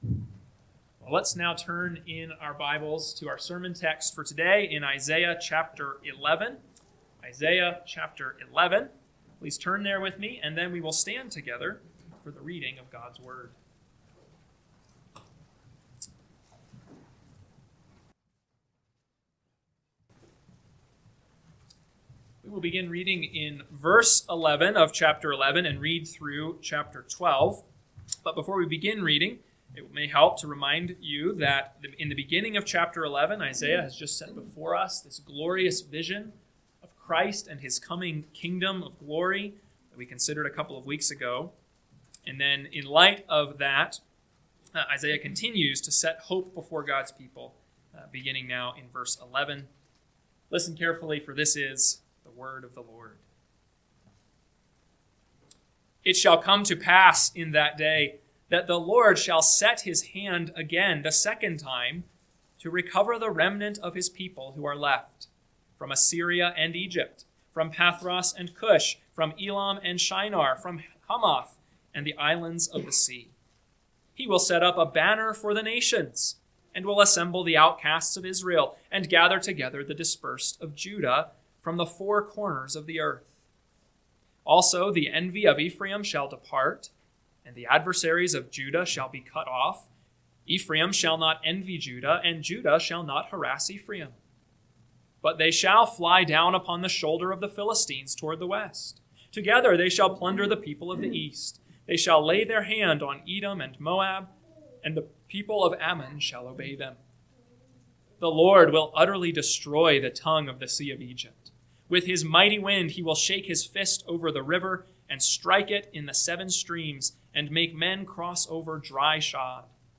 AM Sermon – 2/15/2026 – Isaiah 11:11-12:6 – Northwoods Sermons